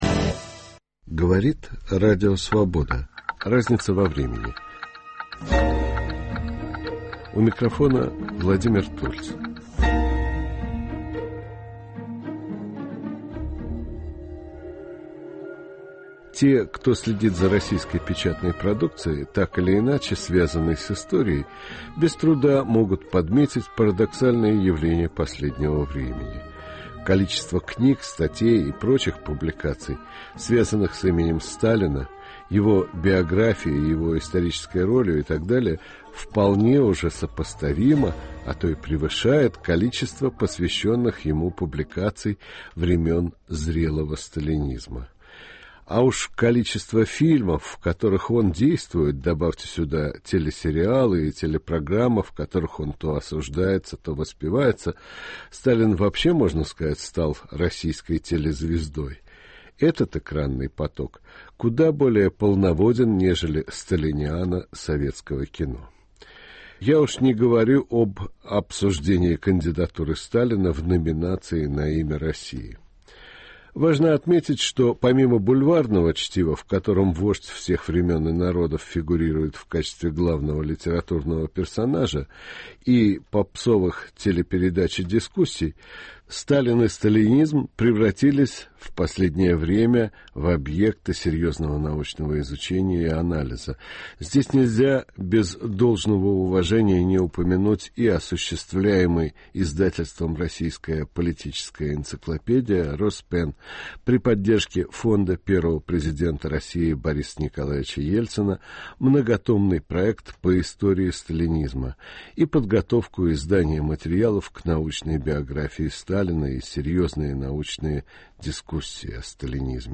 беседует с организаторами Международной конференции по итогам и проблемам изучения сталинизма